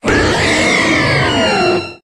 Cri d'Yveltal dans Pokémon HOME.